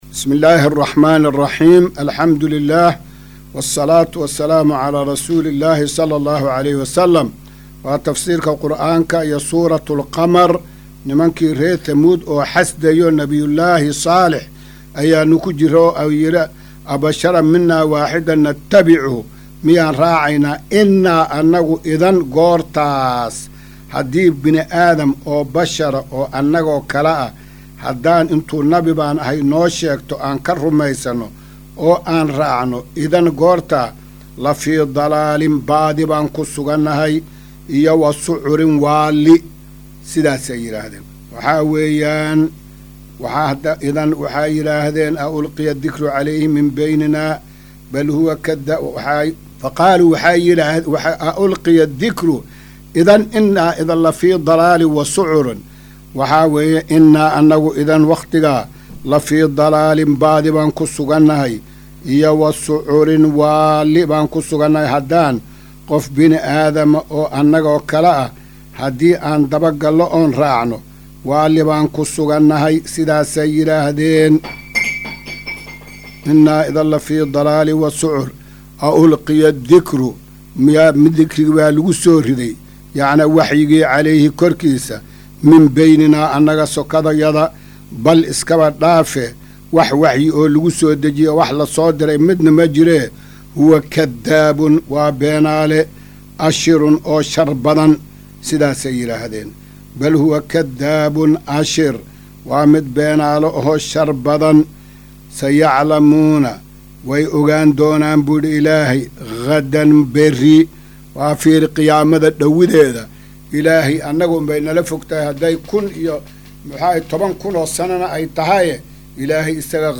Maqal:- Casharka Tafsiirka Qur’aanka Idaacadda Himilo “Darsiga 252aad”